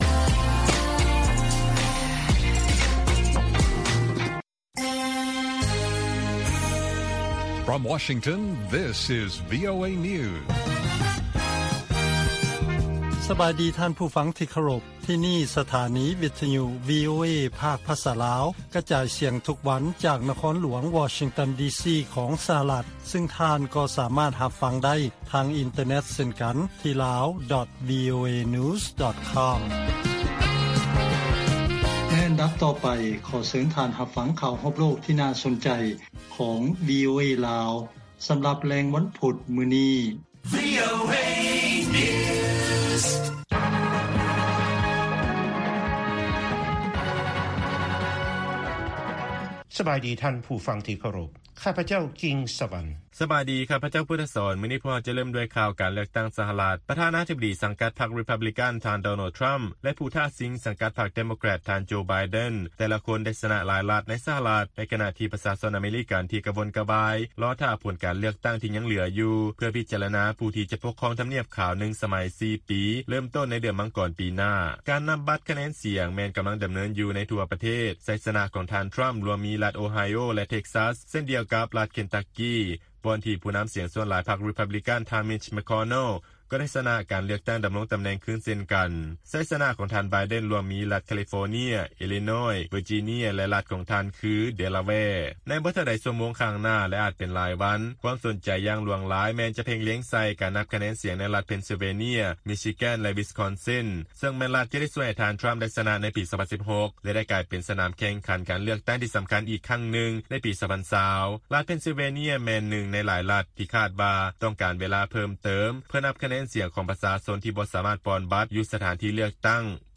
ລາຍການກະຈາຍສຽງຂອງວີໂອເອ ລາວ
ວີໂອເອພາກພາສາລາວ ກະຈາຍສຽງທຸກໆວັນ. ຫົວຂໍ້ຂ່າວສໍາຄັນໃນມື້ນີ້ມີ: 1) ທ່ານ ທຣຳ ແລະ ທ່ານ ໄບເດັນ ຕ່າງກໍໄດ້ຊະນະ ໃນຫຼາຍລັດ ໃນຂະນະທີ່ຜົນການເລືອກຕັ້ງ ພວມຫຼັ່ງໄຫຼເຂົ້າມາ.